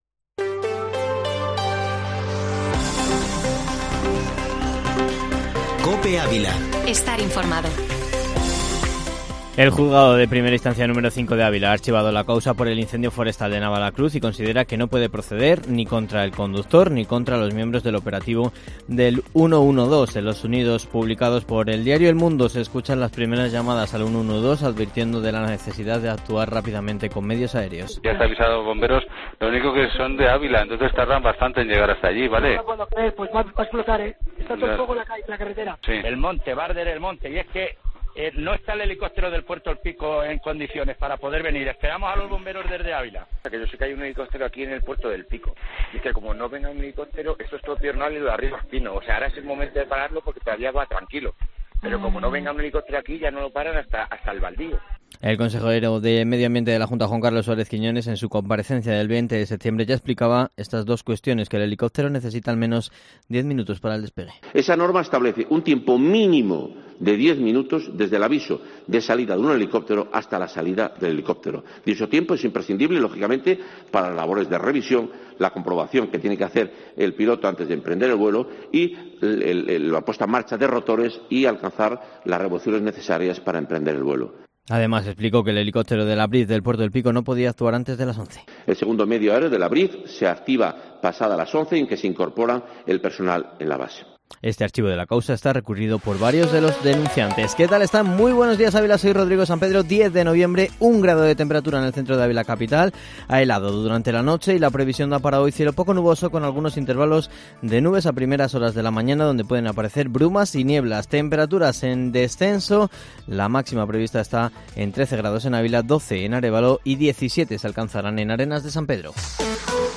Informativo Matinal Herrera en COPE Ávila -10-nov